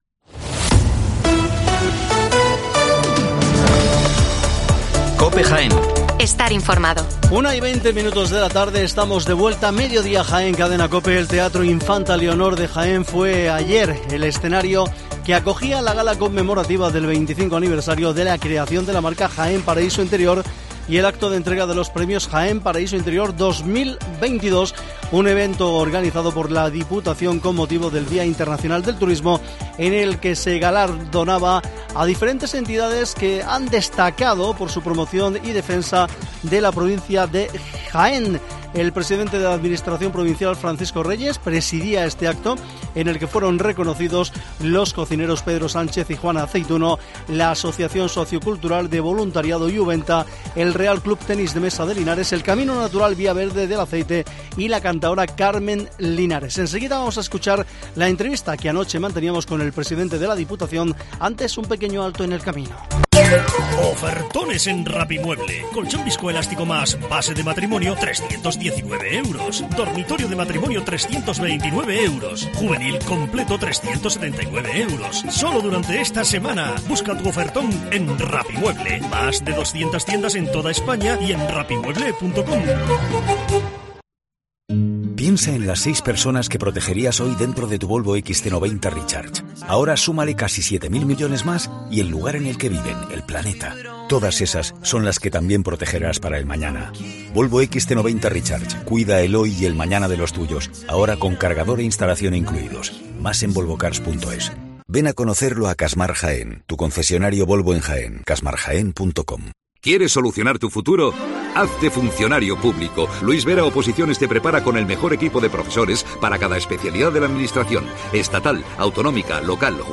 Hoy en COPE charlamos con Francisco Reyes, Presidente de la Diputación de Jaén